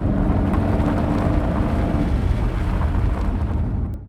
CosmicRageSounds / ogg / general / cars / reverse.ogg